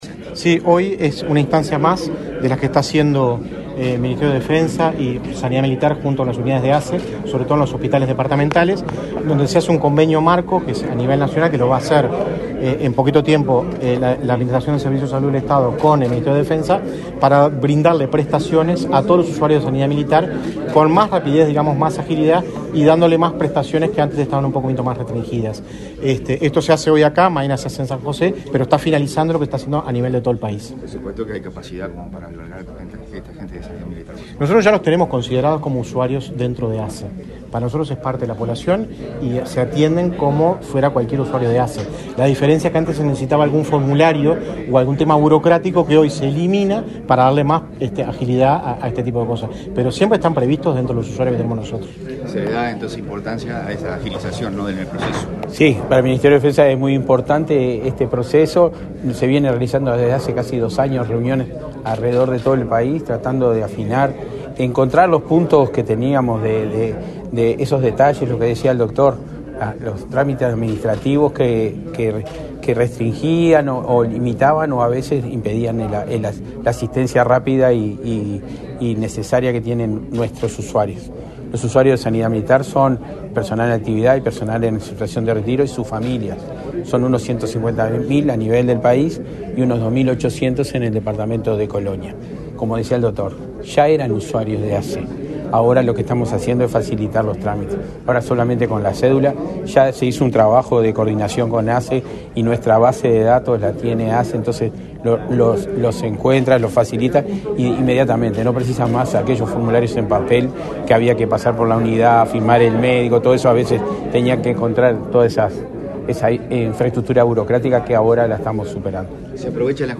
Declaraciones a la prensa del subsecretario de Defensa, Rivera Elgue